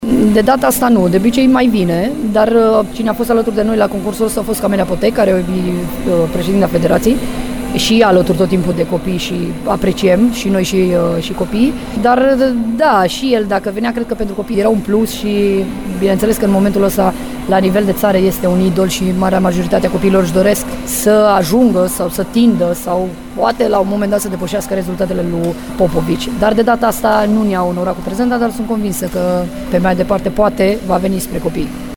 Intervievată de corespondentul nostru